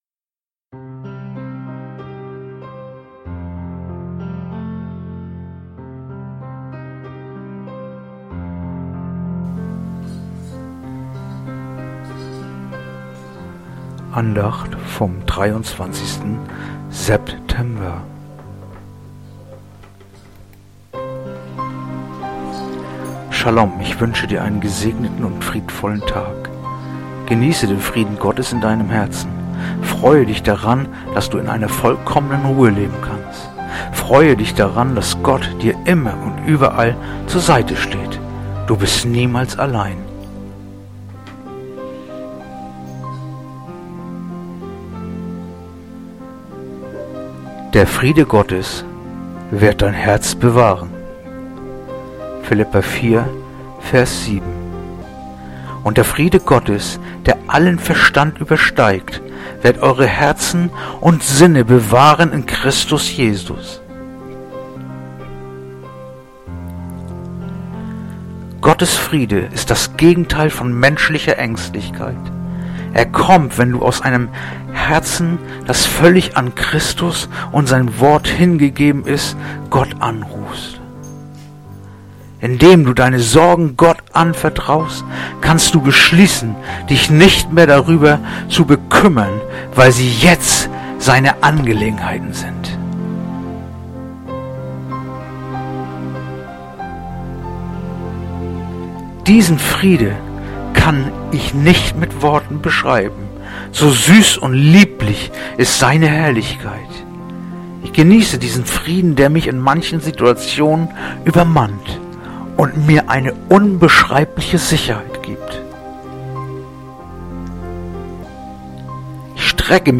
Andacht-vom-23-September-Philipper-4-7.mp3